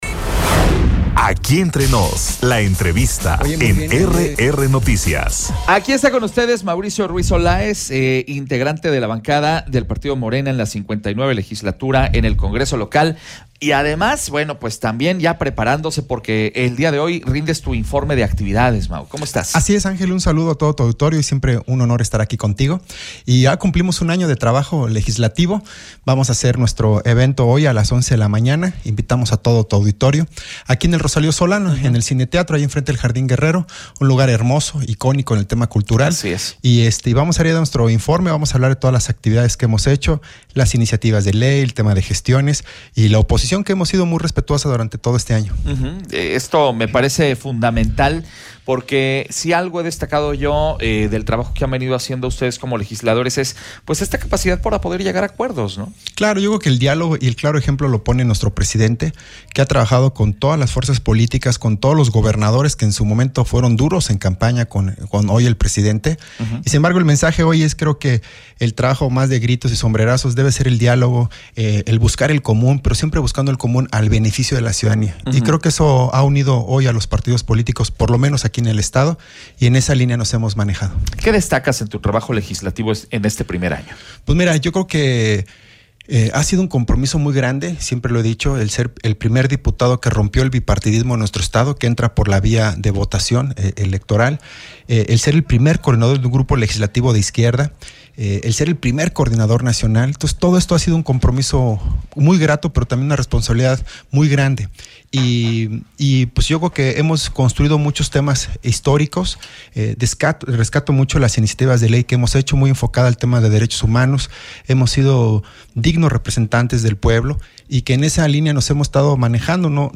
EntrevistasMultimediaPortada
ENTREVISTA-MAURICIO-RUIZ-OLAES.mp3